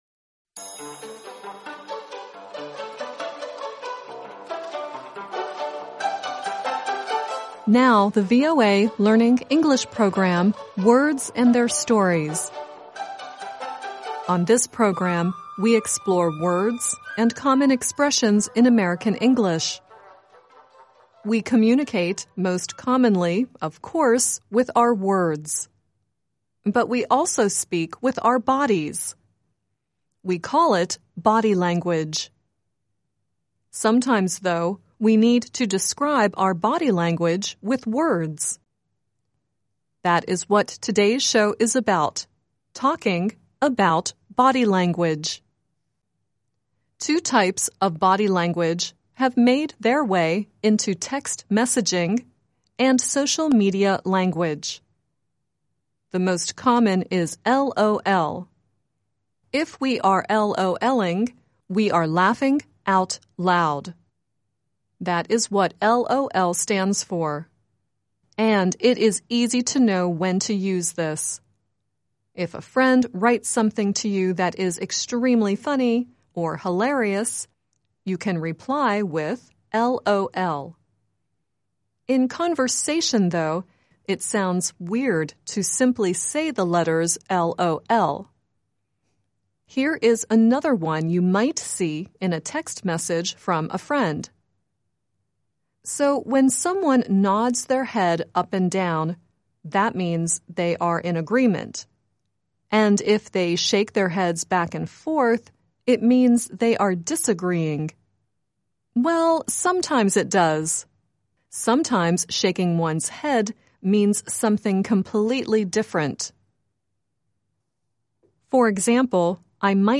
The song at the end is "Body Language" by Queen.